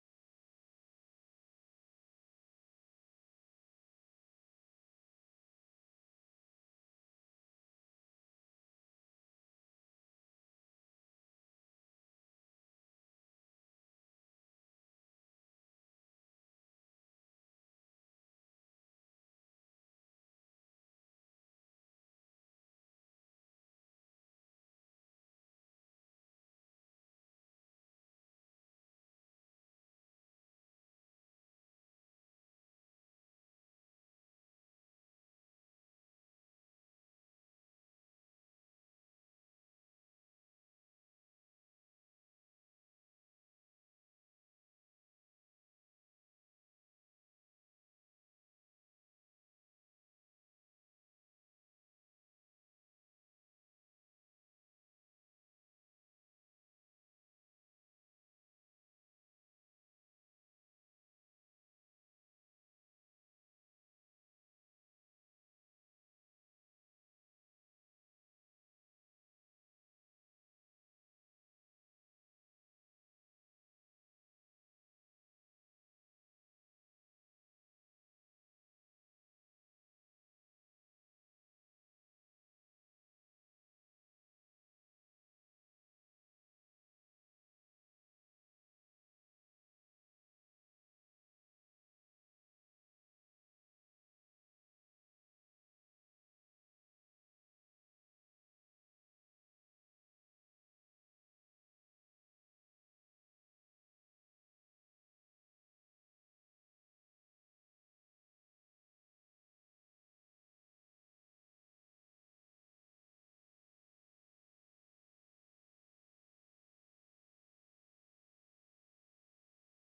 Sons of Liberty Radio Talk Show